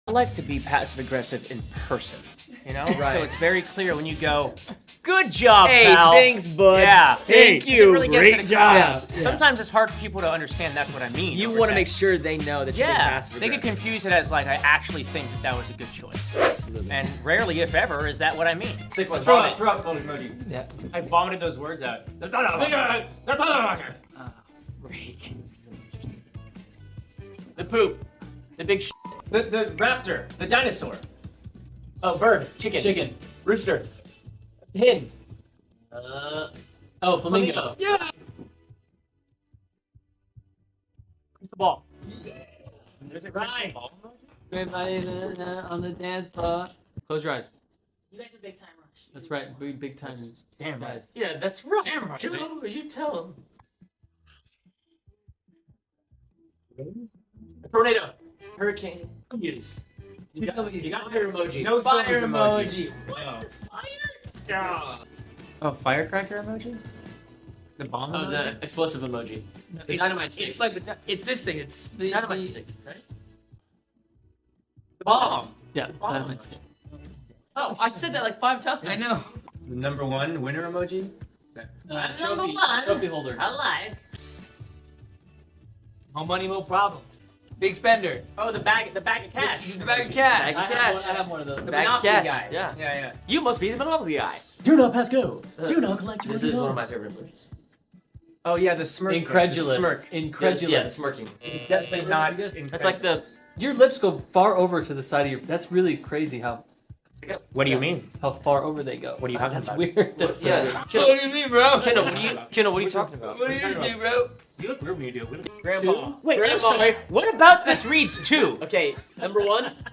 A Big Time Rush funny moments from an interview.